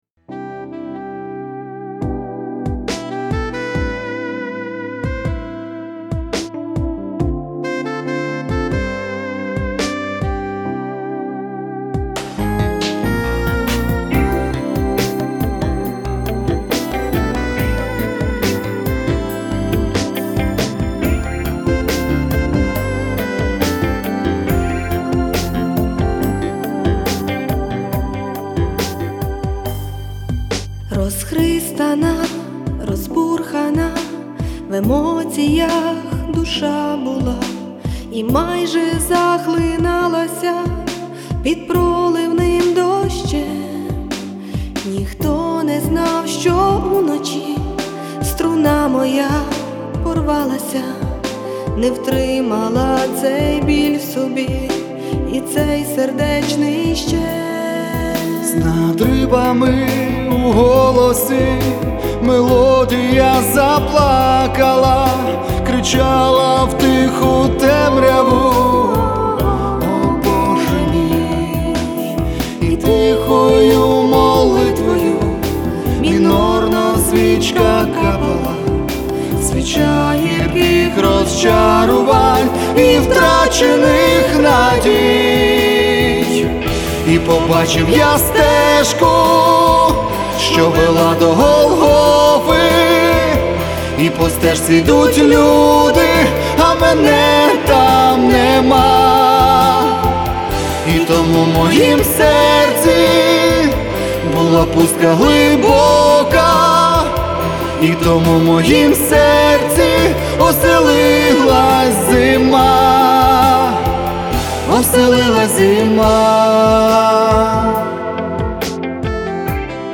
Музична Поезія